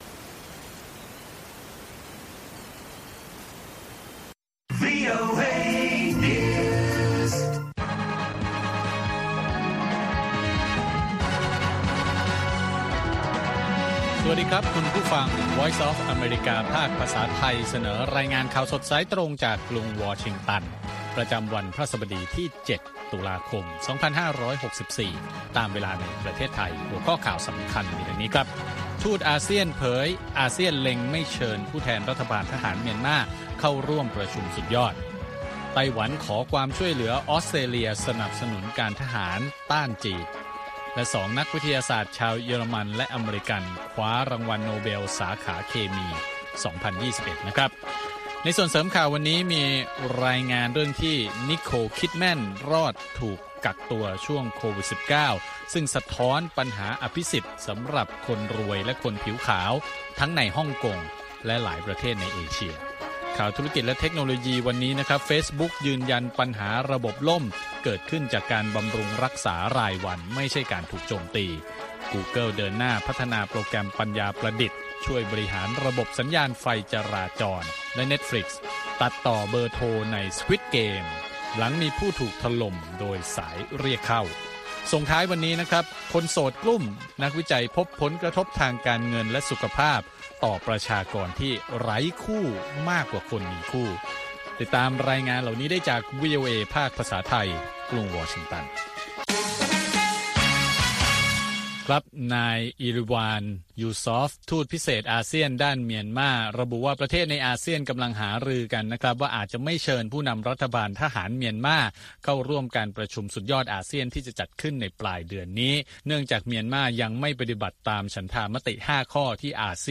ข่าวสดสายตรงจากวีโอเอ ภาคภาษาไทย 8:30–9:00 น. ประจำวันพฤหัสบดีที่ 7 ตุลาคม 2564 ตามเวลาในประเทศไทย